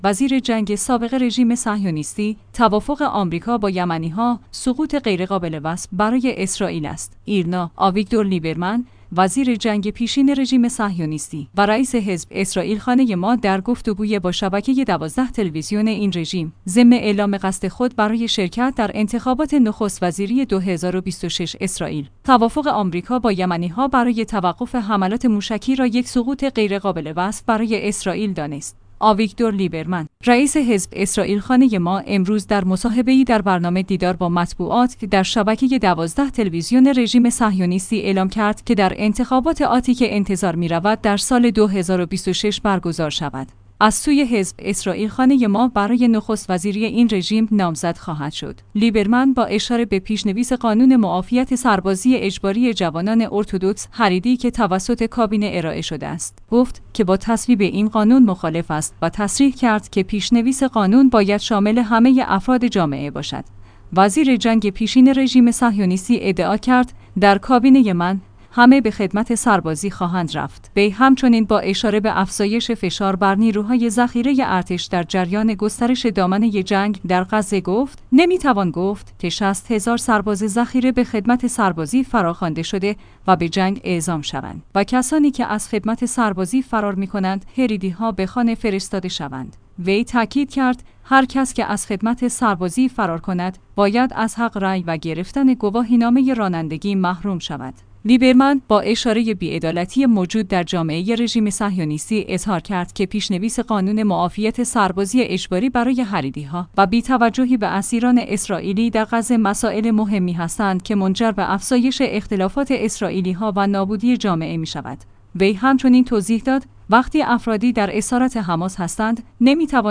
ایرنا/ آویگدور لیبرمن، وزیر جنگ پیشین رژیم صهیونیستی و رئیس حزب «اسرائیل خانه ما» در گفت‌وگوی با شبکه ۱۲ تلویزیون این رژیم، ضمن اعلام قصد خود برای شرکت در انتخابات نخست‌وزیری ۲۰۲۶ اسرائیل، توافق آمریکا با یمنی‌ها برای توقف حملات موشکی را یک سقوط غیرقابل وصف برای اسرائیل دانست.